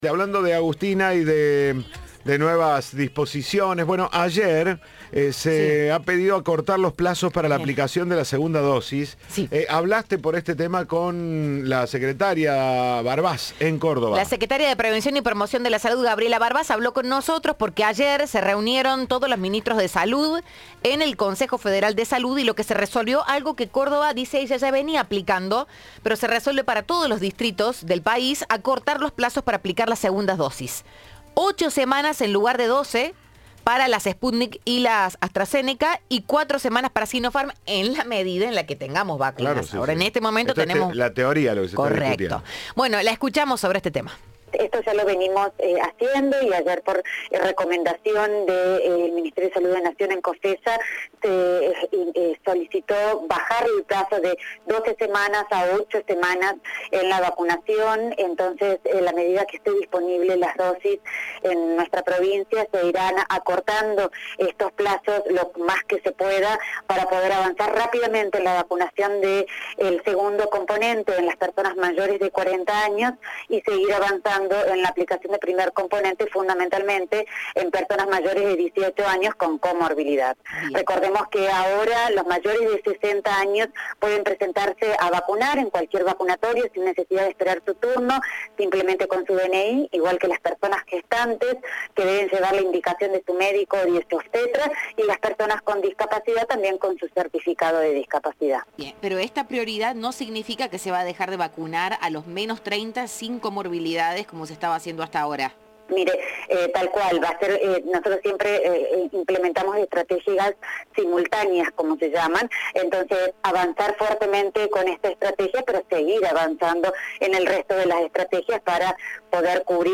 La secretaria de Prevención y Promoción de Salud, Gabriela Barbas, dijo que tras la reunión con el Consejo Federal se acordó acelerar la colocación del segundo componente en los mayores de 40.